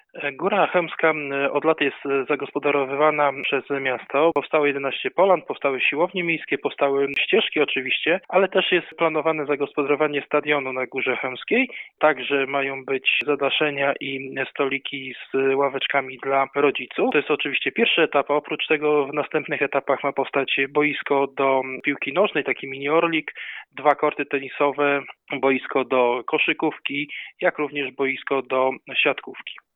Opowiada Zastępca Prezydenta Koszalina Wojciech Kasprzyk.